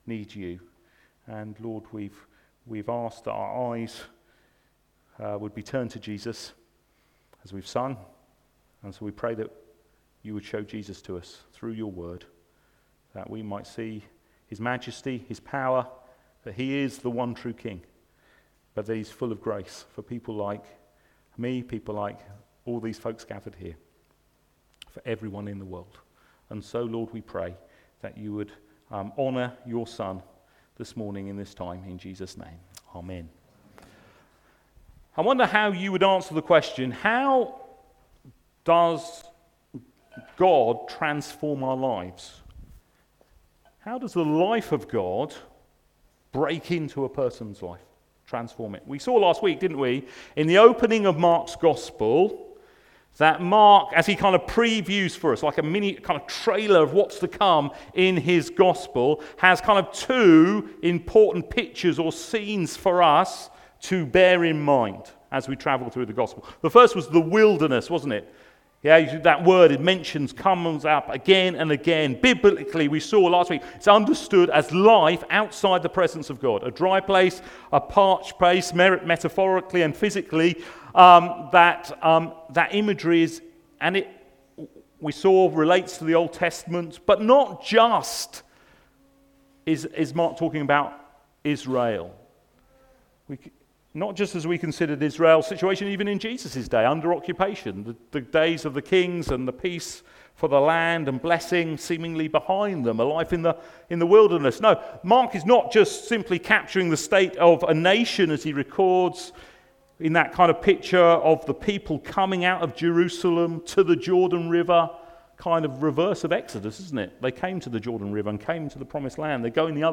LABC Sermons